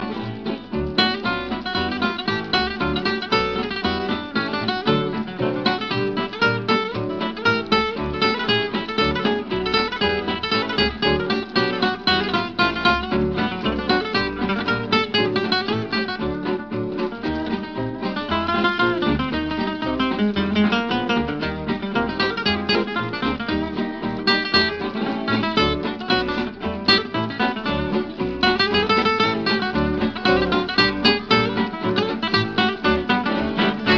Pretty weak I'd say.